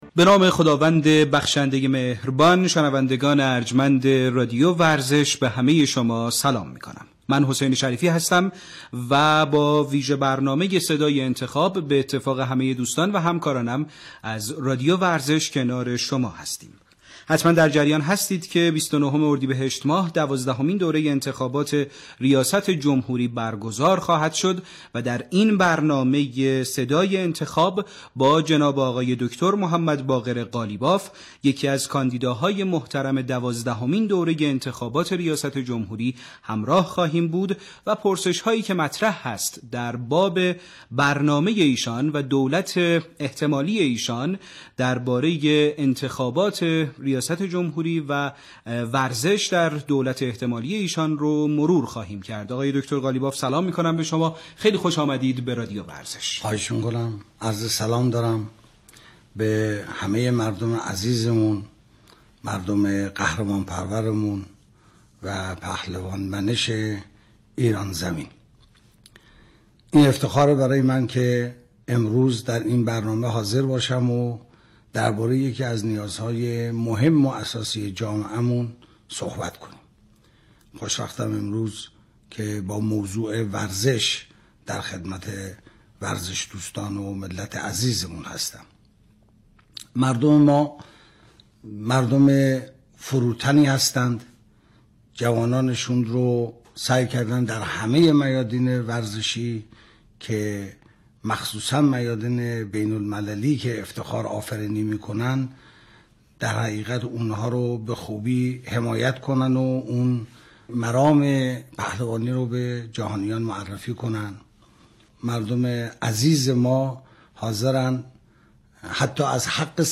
به گزارش خبرنگار سیاسی خبرگزاری تسنیم، محمدباقر قالیباف کاندیدای دوازدهمین دوره انتخابات ریاست‌جمهوری در برنامه صدای انتخاب رادیو ورزش، با اشاره به اینکه مردم ایران مردمی هستند که قهرمانان جوان خود را به سمت پهلوانی رشد می‌دهند و مسئولان را وادار کردند که از ورزش قهرمانی حمایت کنند، اظهار داشت: این مردم هستند که احساس غرور و خودباوری را به مسئولان و ورزشکاران منتقل می‌کنند.